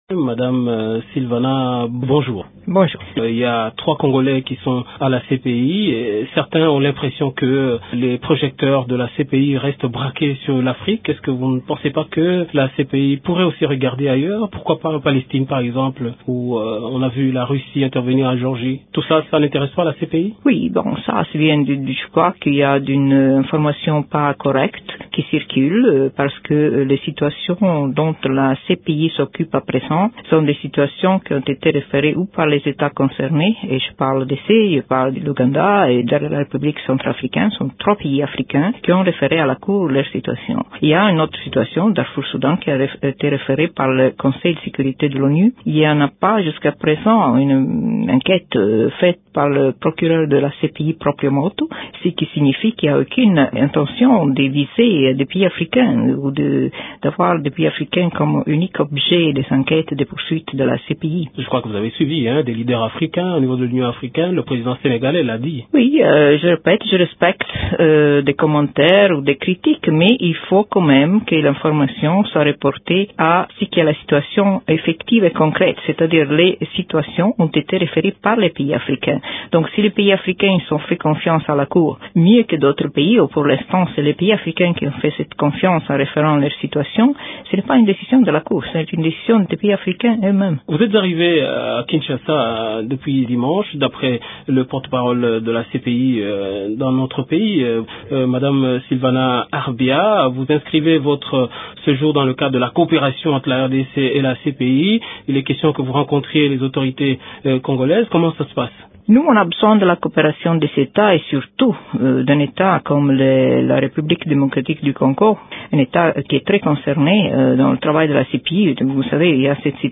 En visite à Kinshasa, Arbia Sylvana, greffier de la cpi parle du travail de la Cpi et des congolais qui sont détenus à la Haye.